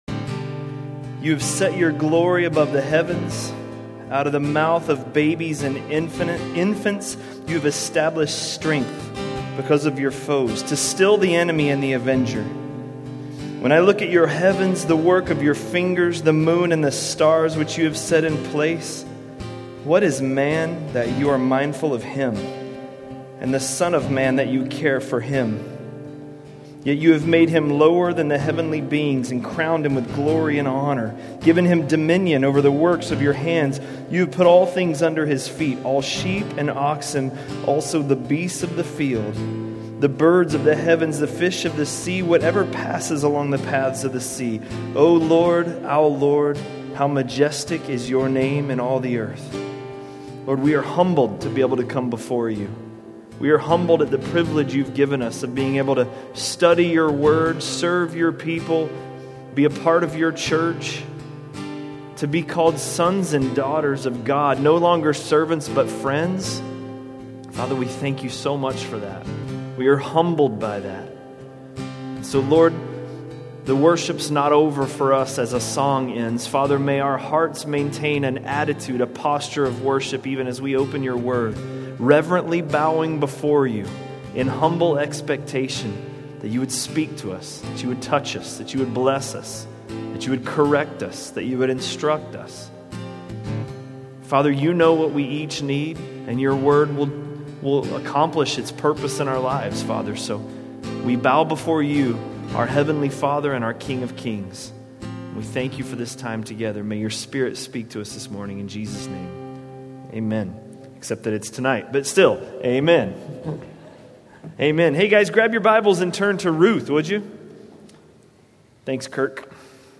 A message from the series "Ruth." Ruth 2:1–2:13